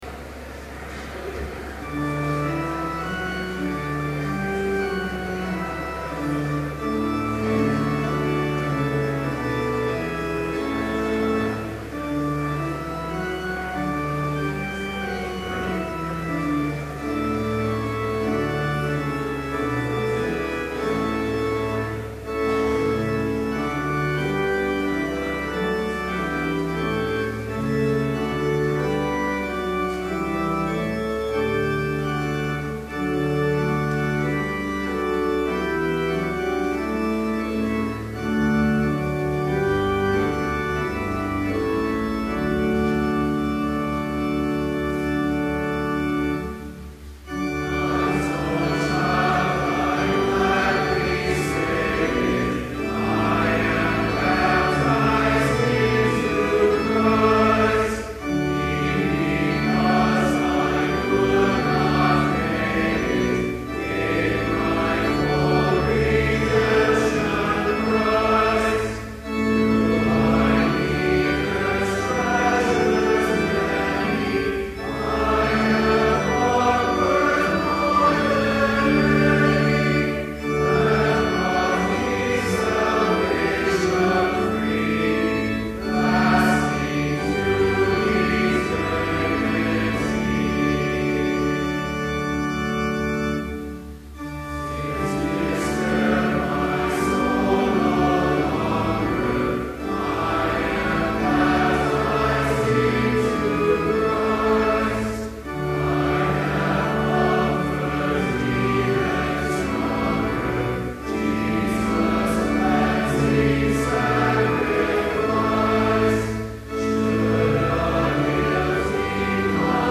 Complete service audio for Chapel - February 8, 2012